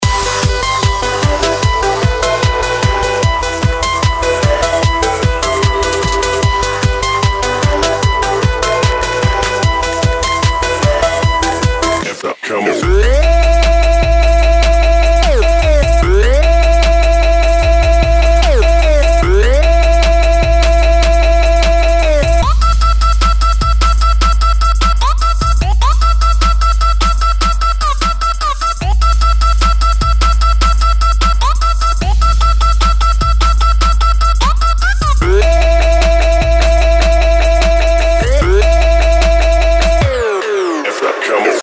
dubstep